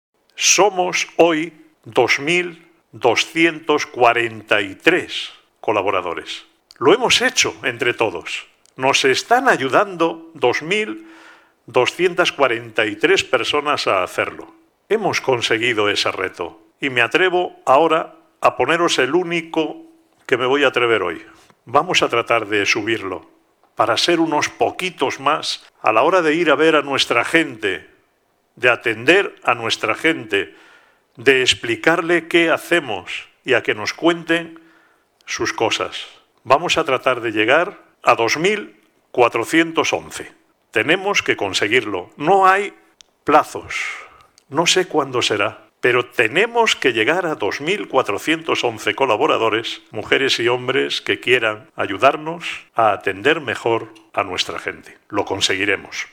Un llamamiento que hizo el último Comité de Coordinación General (CCG) organizado por el Consejo General de la Organización los pasados 26 y 27 de noviembre, desde la sede de Fundación ONCE, ante un restringido grupo de asistentes presenciales debido al Covid, pero que conectó online a casi 160 personas de todos los puntos de la geografía española.